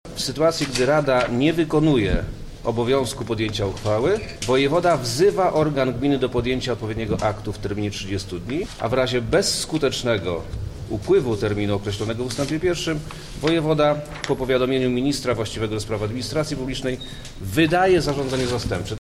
– Wojewoda nie „może”, a „musi” wezwać Radę Miasta do stwierdzenia wygaśnięcia mandatu prezydenta w takim przypadku – mówi wojewoda lubelski Przemysław Czarnek